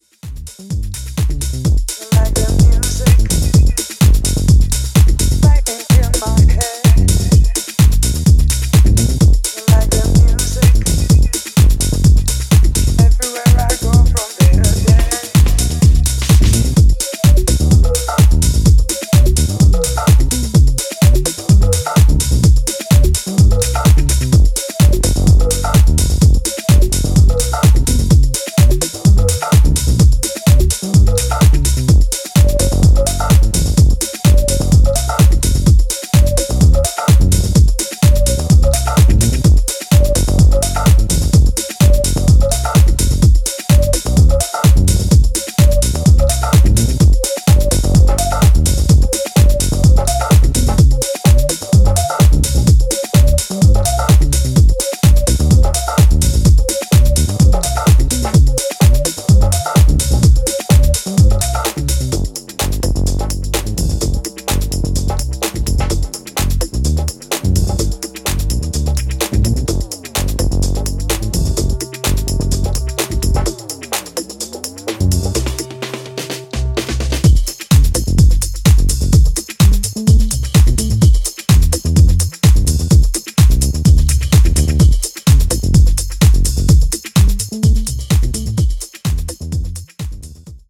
ジャンル(スタイル) TECH HOUSE / HOUSE